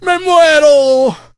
el_primo_death_02.ogg